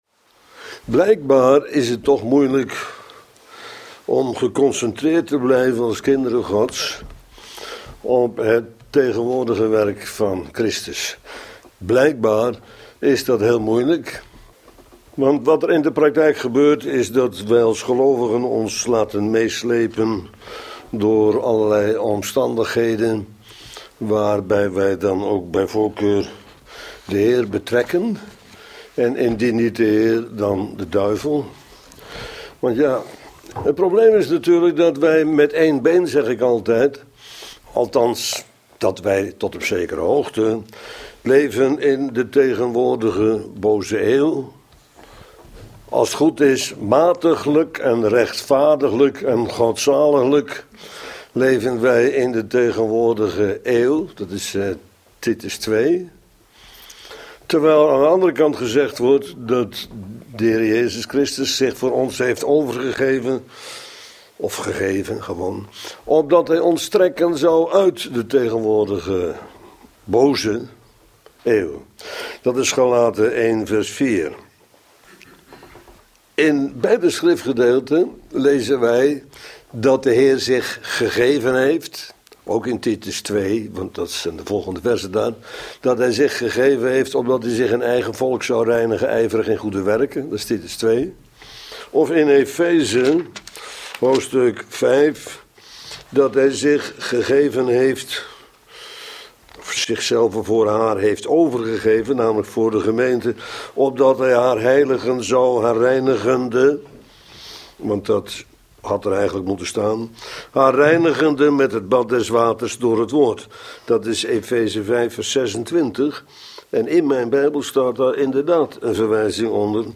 Bijbelstudie, Een 'welriekende reuk' voor de Vader bracht Christus na Zijn opstanding, toen Hij Zijn Gemeente offerde, naar de typen in de Oude Testament van dank-, spijs- en brandoffer. De kruisdood van Jezus betekende anderzijds Zijn ultieme vernedering.